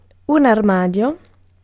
Ääntäminen
Synonyymit shrank costume department clothes cupboard hanging cupboard (brittienglanti) press (amerikanenglanti) closet (brittienglanti) cupboard Ääntäminen US UK UK : IPA : / ˈwɔːdɹəʊb/ US : IPA : /ˈwɔːɹdɹoʊb/